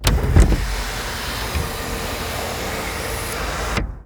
servo.wav